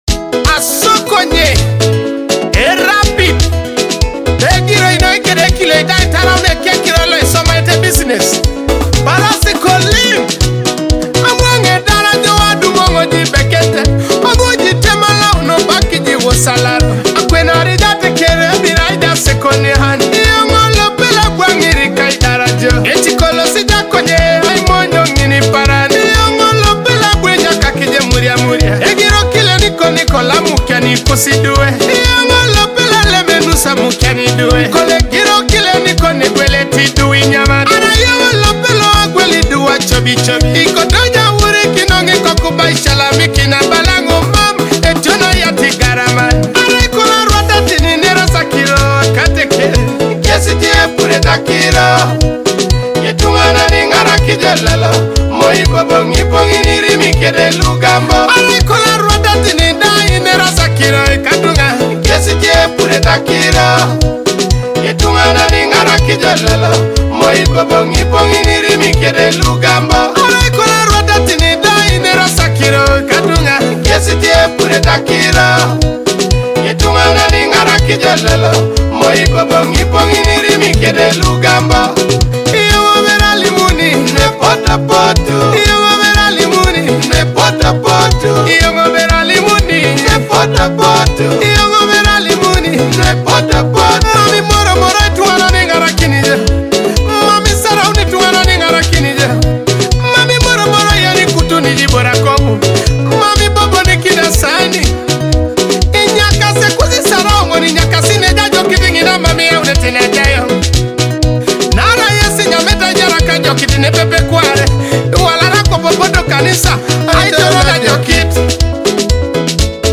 Teso music